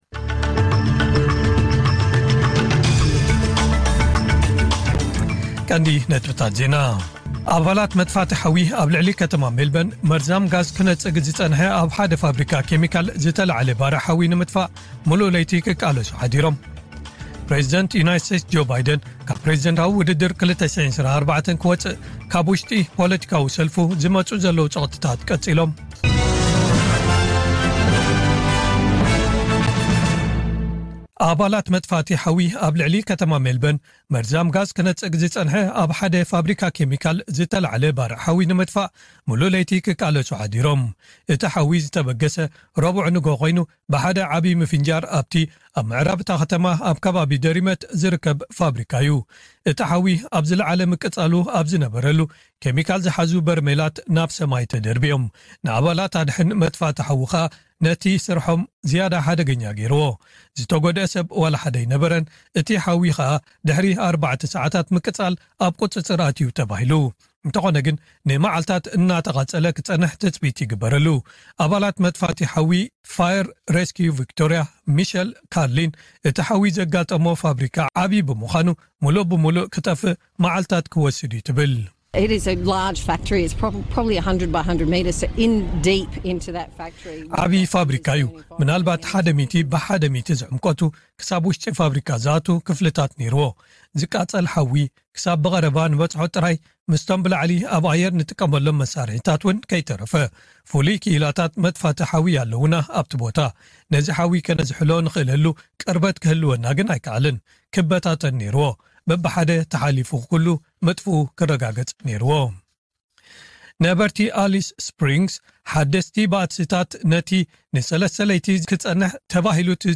ዕለታዊ ዜና ኤስ ቢ ኤስ ትግርኛ (11 ሓምለ 2024)